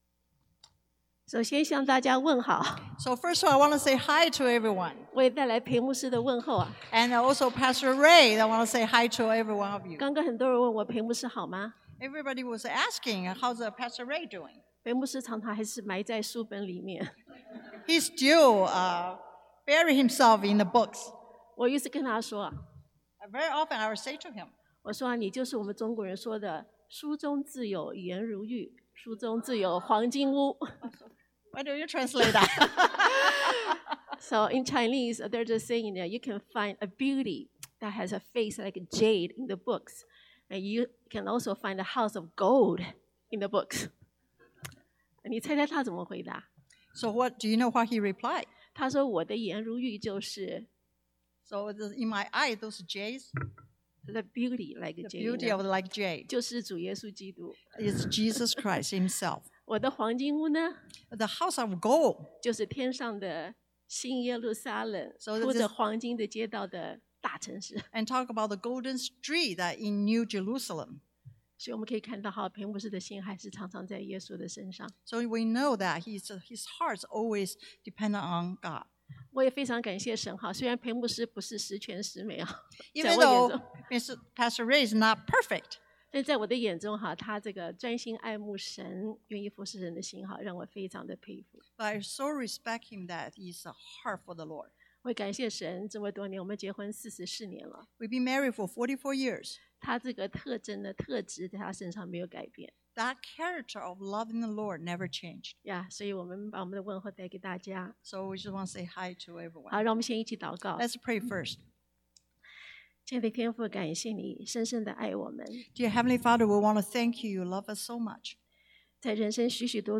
列王記上 1 Kings 3:7-14 Service Type: Sunday AM Jesus said